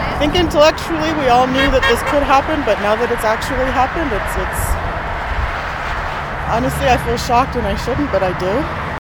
A woman from a military family was present at the overpass and watched with great emotion as Nathan Cirillo’s motorcade passed by. The woman didn’t want to give her name but she spoke about her heartache after hearing what happened in Ottawa .